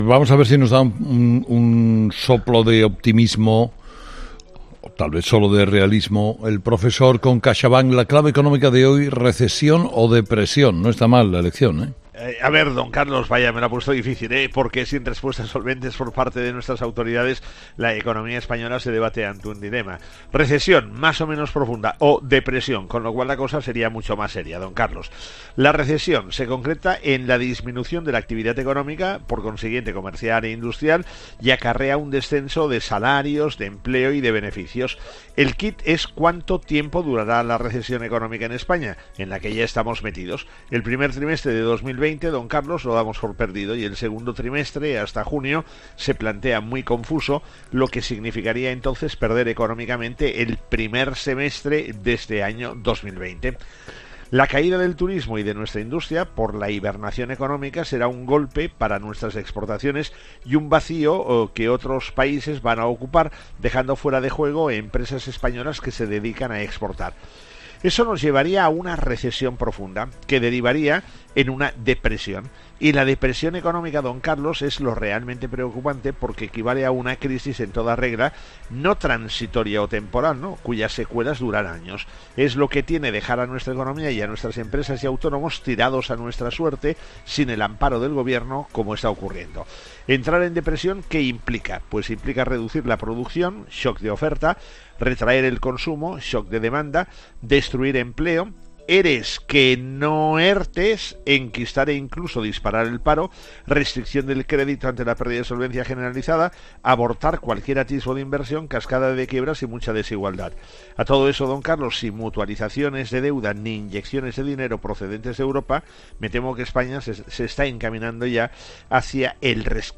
El profesor José María Gay de Liébana analiza en ‘Herrera en COPE’ las claves económicas del día.